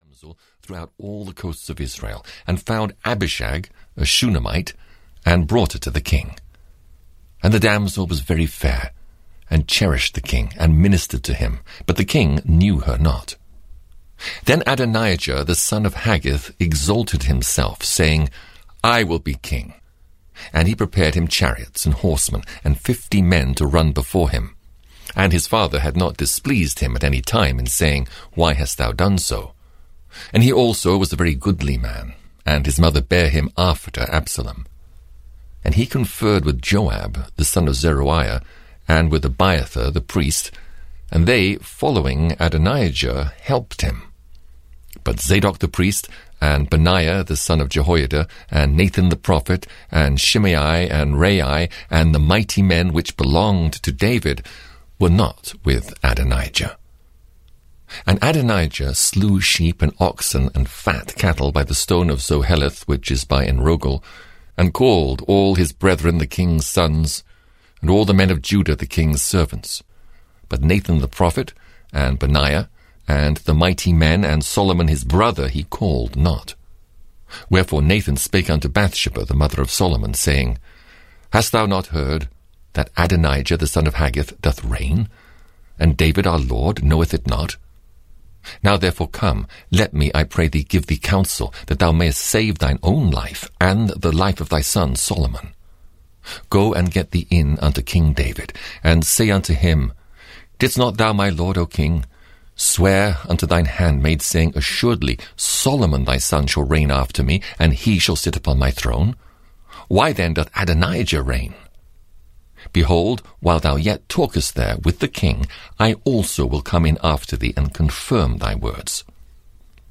The Old Testament 11 - Kings 1 (EN) audiokniha
Ukázka z knihy